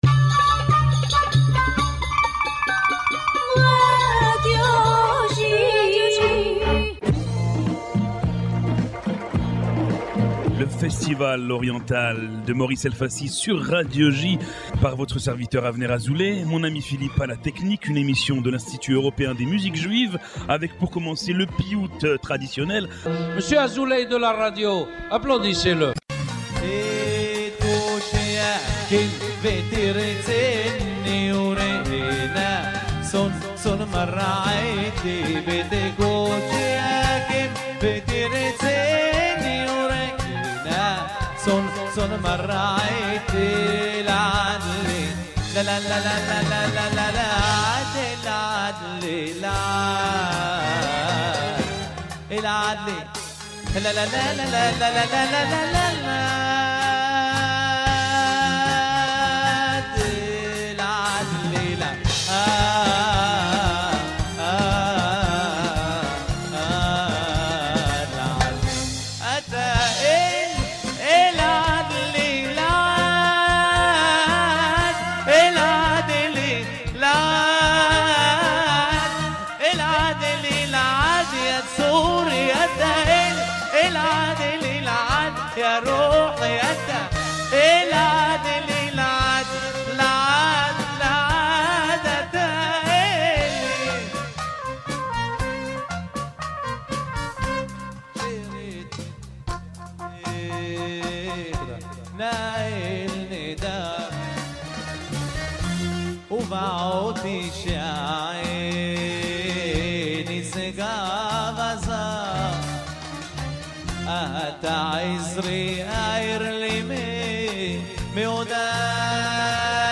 musique orientale
piyout traditionnel yeroushalmi
classique tunisien
classique de Constantine
classique oranais
piyout traditionnel marocain
folklore algérien